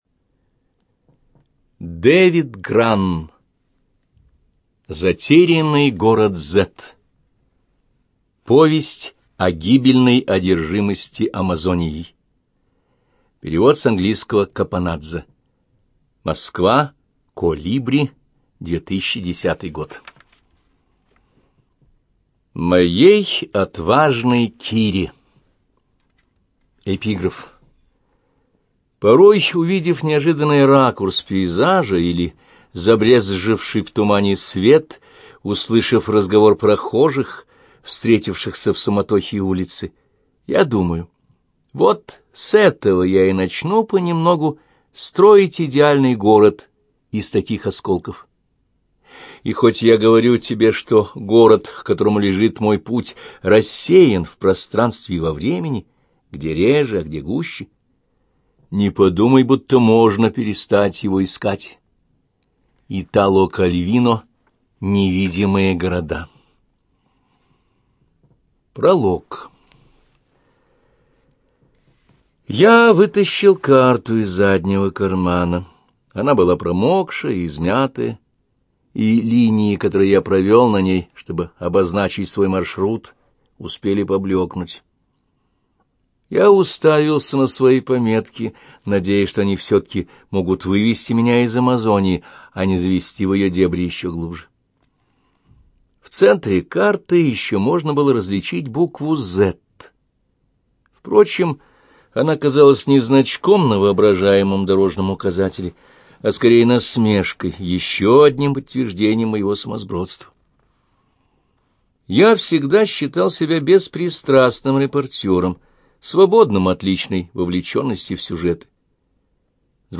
ЖанрПриключения, Документальные фонограммы
Студия звукозаписиЛогосвос